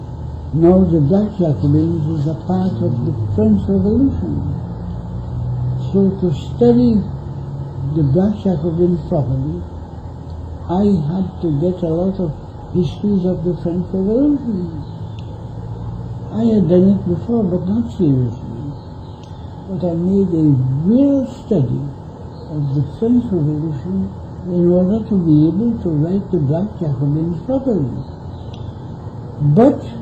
5 audio cassettes
The Oral and Pictorial Records Programme (OPReP)